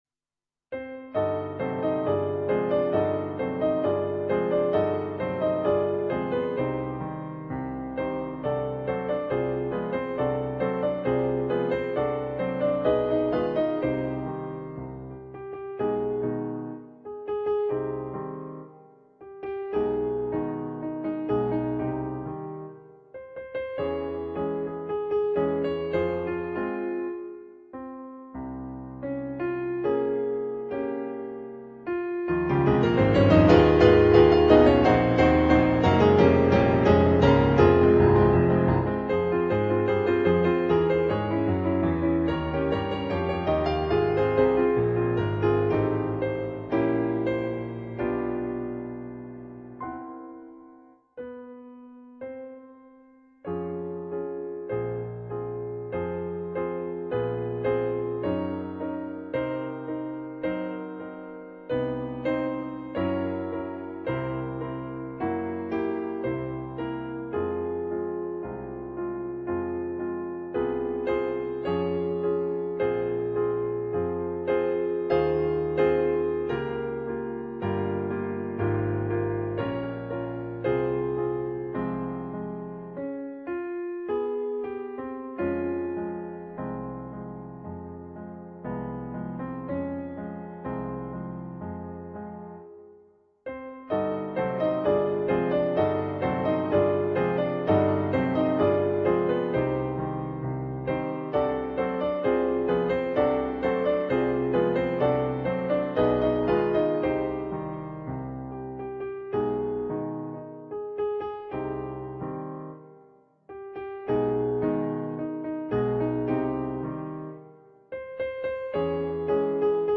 piano solo
on Yamaha digital pianos.